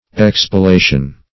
Expilation \Ex`pi*la"tion\, n. [L. expiatio.]